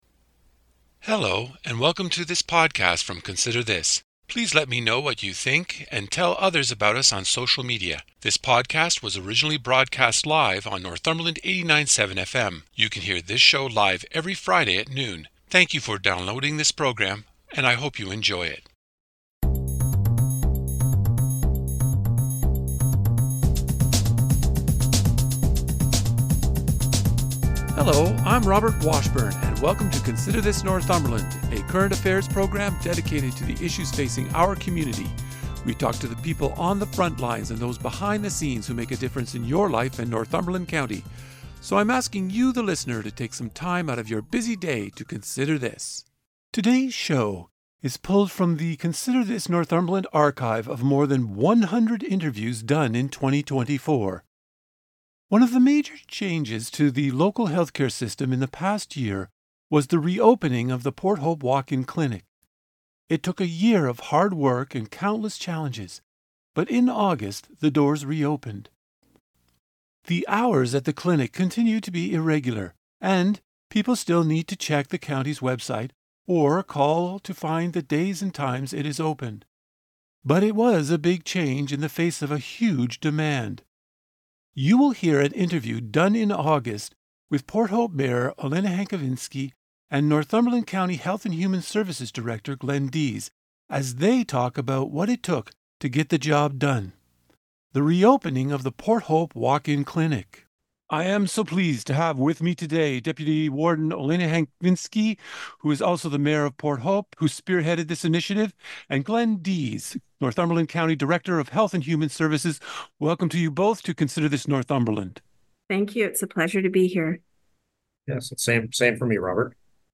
This interview is pulled from the Consider This Northumberland archive of more than 100 interviews done in 2024.